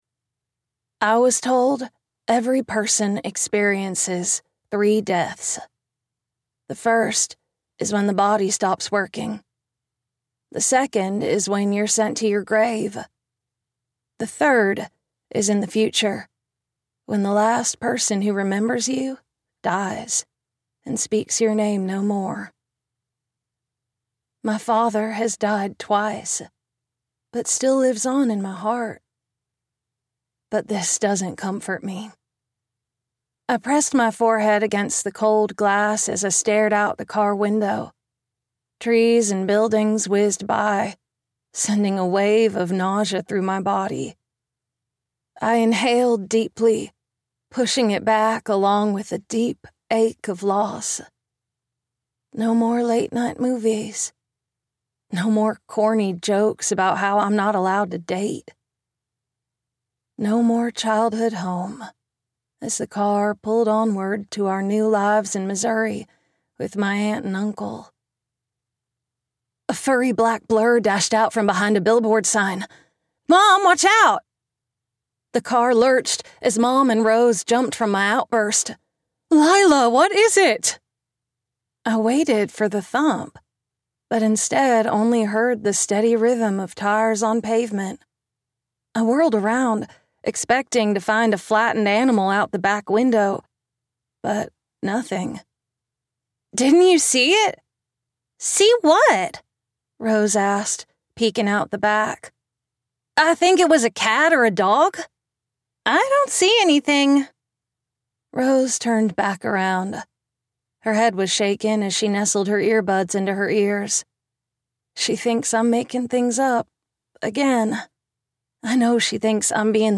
Ask the Girl - Vibrance Press Audiobooks - Vibrance Press Audiobooks